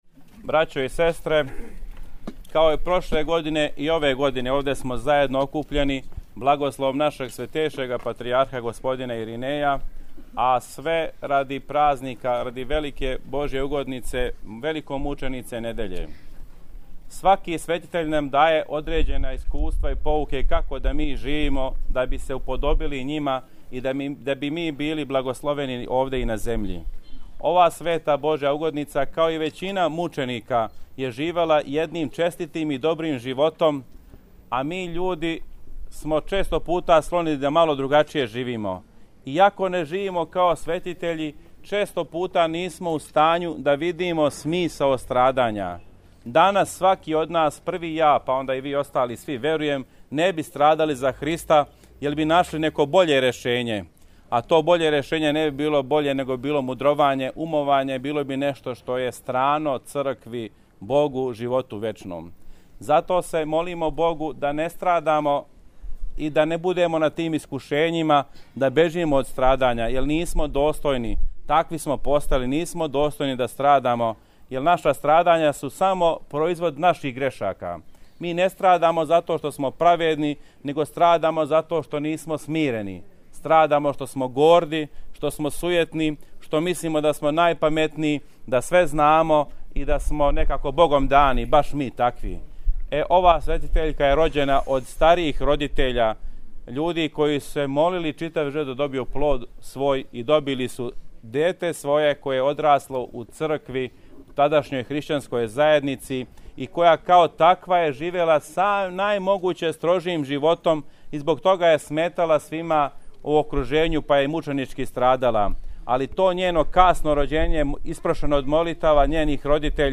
Беседа Епископа Стефана на слави храма Св. мученице Недеље: